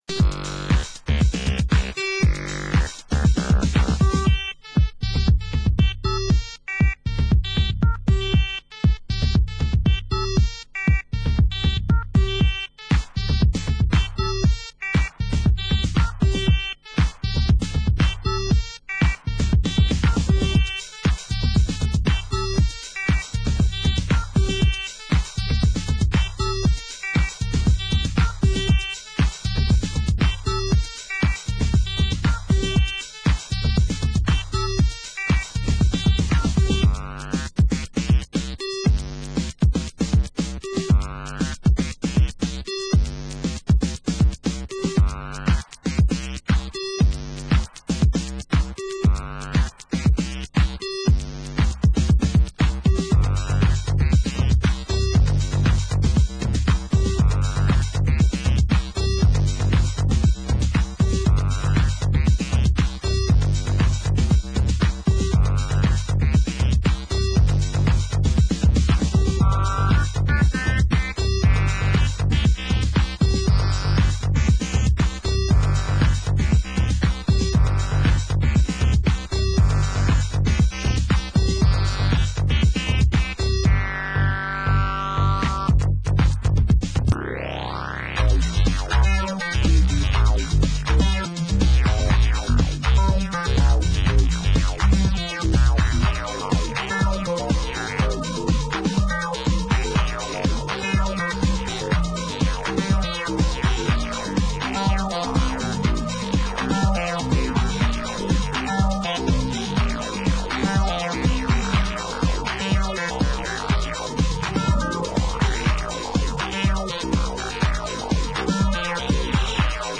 Genre: Acid House